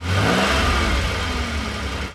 CosmicRageSounds / ogg / general / cars / rev_out4.ogg
rev_out4.ogg